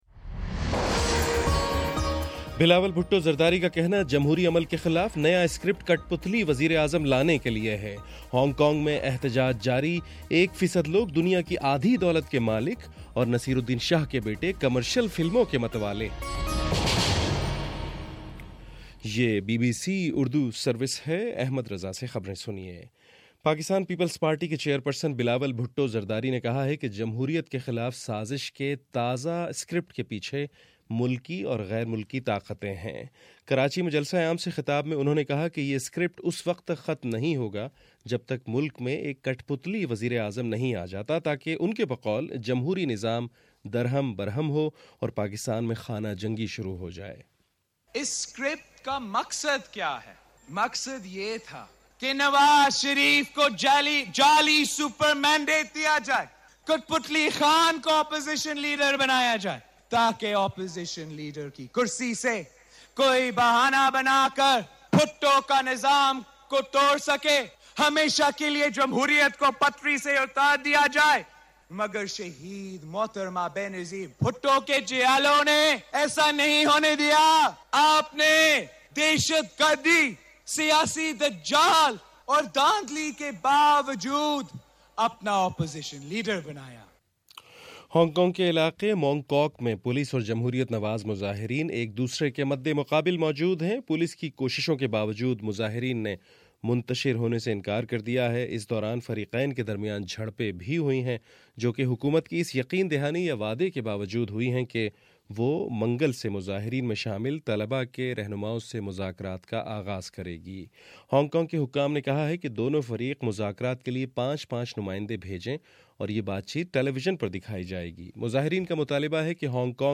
اکتوبر 19 : صبح نو بجے کا نیوز بُلیٹن